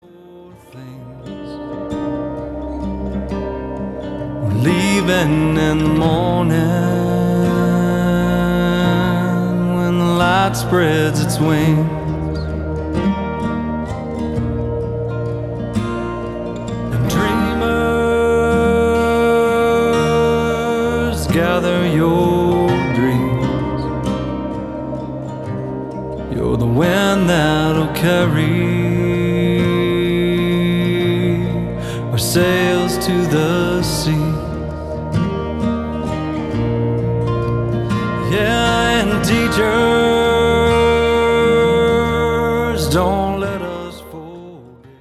Country, Jewish, Americana